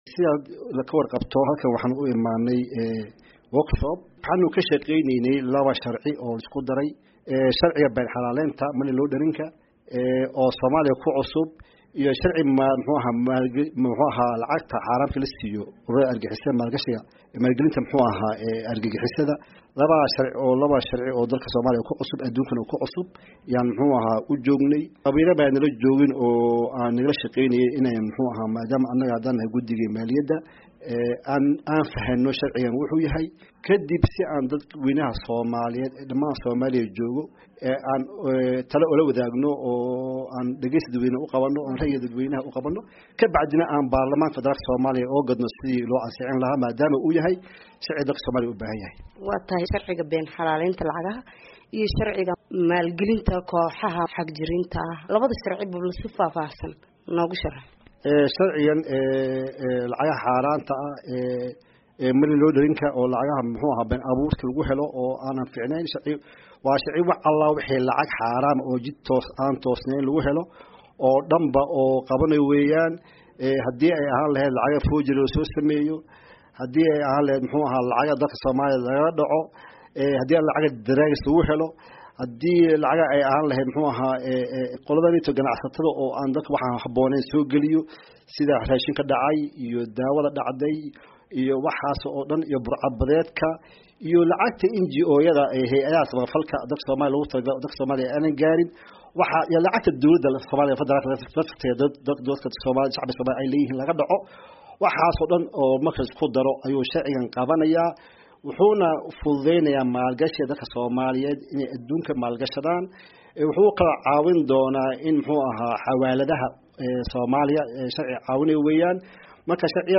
Wareysi: Xildhibaan Maxamed Iidle